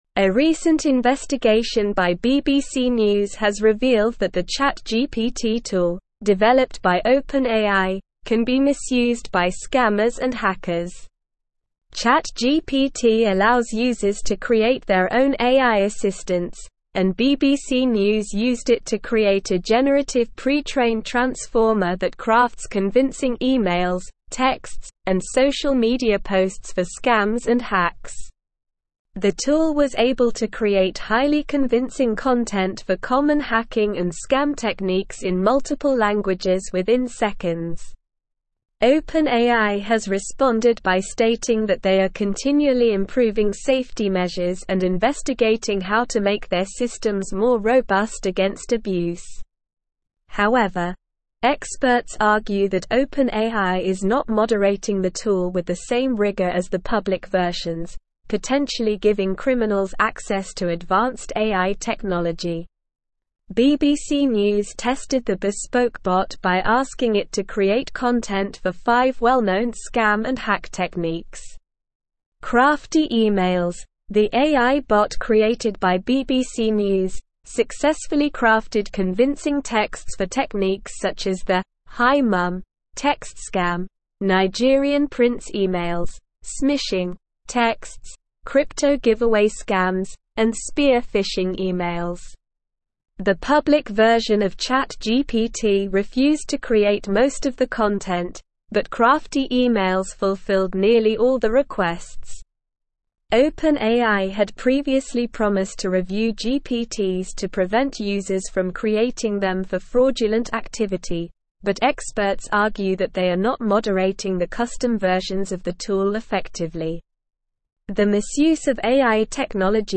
Slow
English-Newsroom-Advanced-SLOW-Reading-OpenAIs-ChatGPT-Tool-Raises-Concerns-About-Cybercrime.mp3